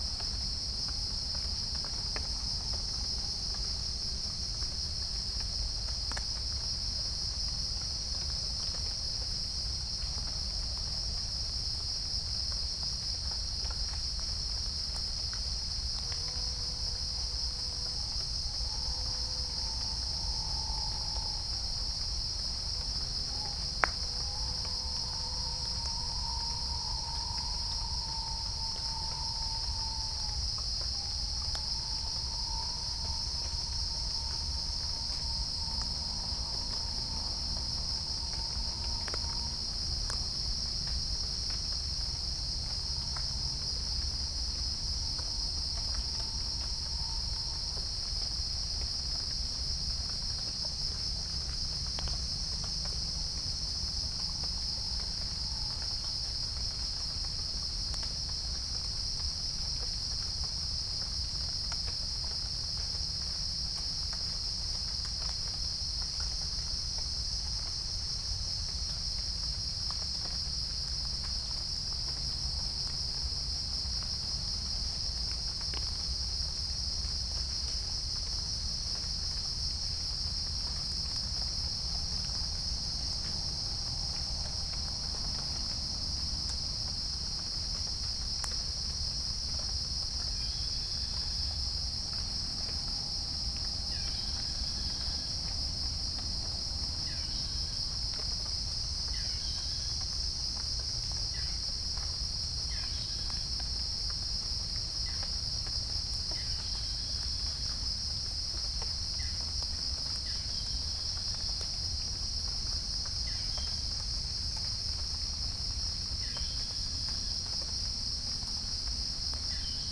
Geopelia striata
Pycnonotus goiavier
Halcyon smyrnensis
Orthotomus ruficeps
Dicaeum trigonostigma